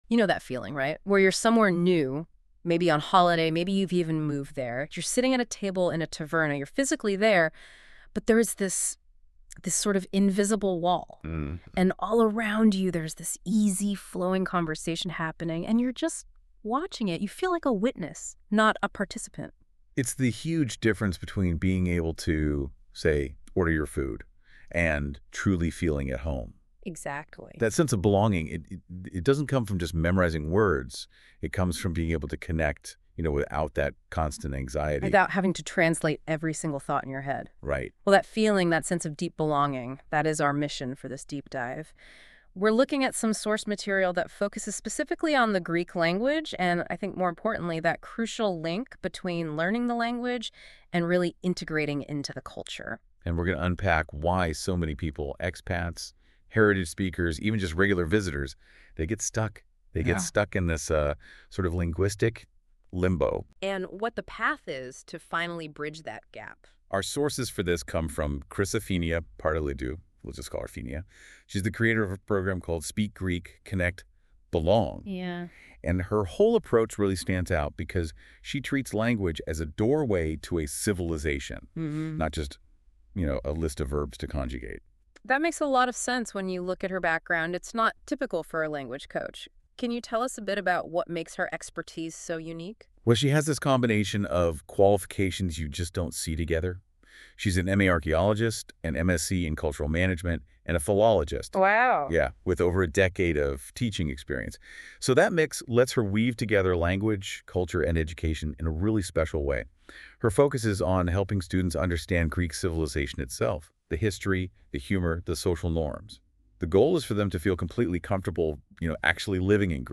The episode is narrated with the help of AI, but the ideas, method, and teaching philosophy come entirely from my own experience working with foreign adults who want more than grammar — they want to communicate, connect, and feel at home in Greece.